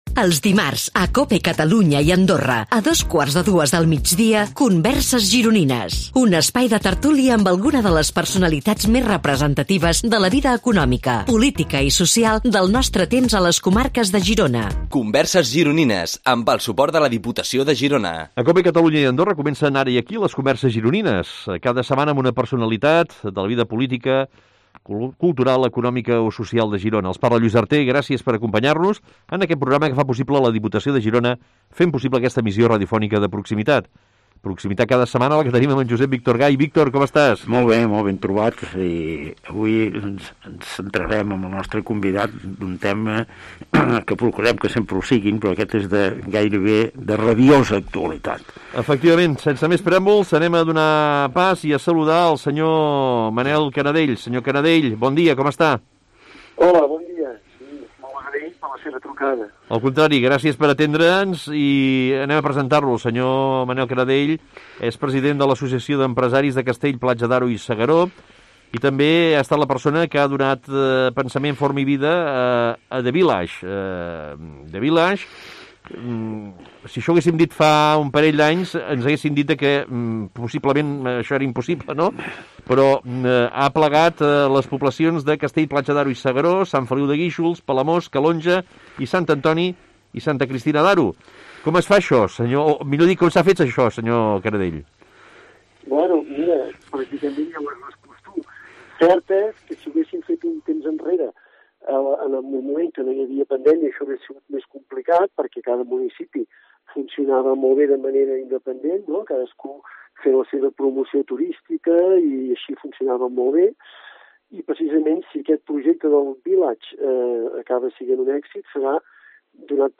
Aquestes converses es creen en un format de tertúlia en el que en un clima distès i relaxat els convidats ens sorprenen pels seus coneixements i pel relat de les seves trajectòries. Actualment el programa s’enregistra i emet en els estudis de la Cadena Cope a Girona, situats en el carrer de la Sèquia número tres de Girona, just al costat del museu del Cinema.